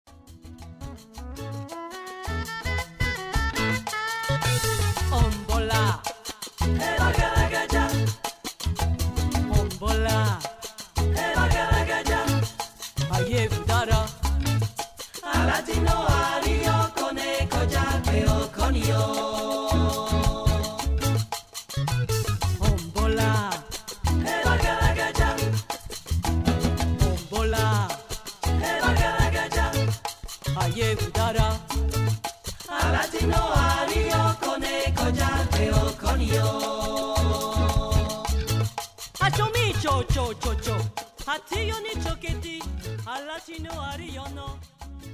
une voix qui chante l'Afrique
World Musique
World Music